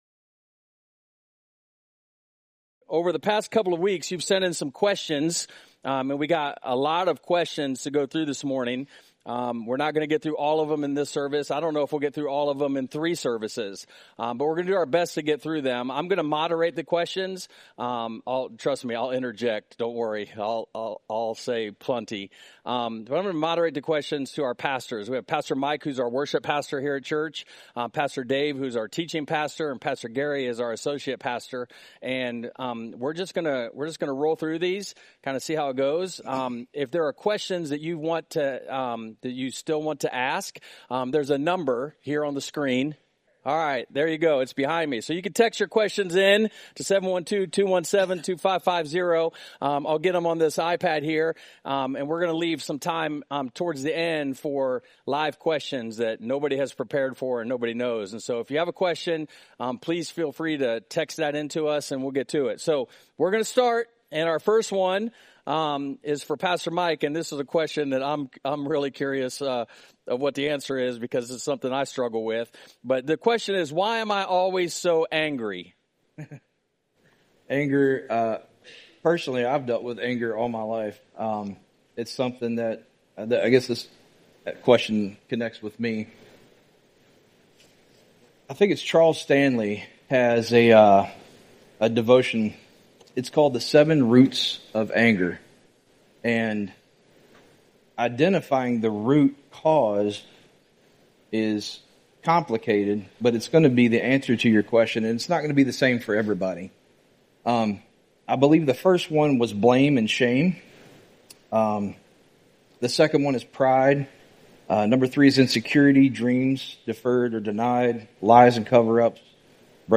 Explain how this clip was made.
Please watch the other videos from this Sunday, as there were different questions at each service.